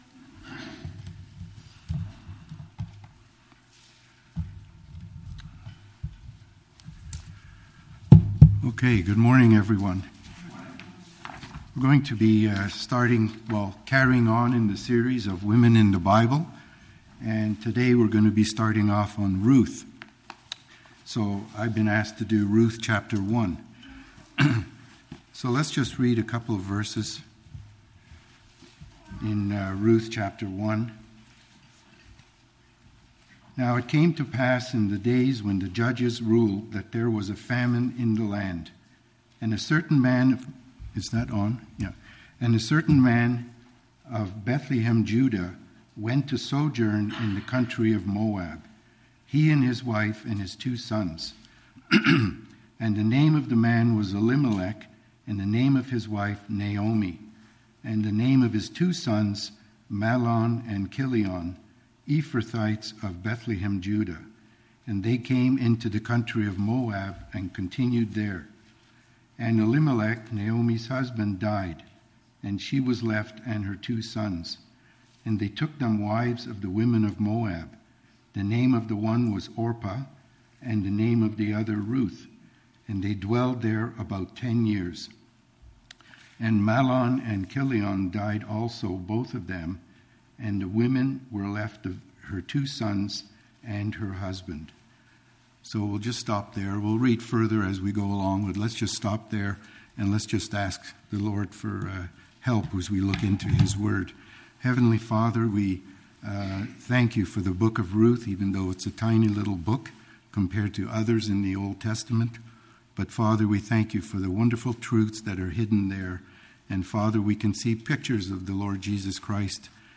Ruth Ch 1 Service Type: Family Bible Hour First of 4 messages on the book of Ruth.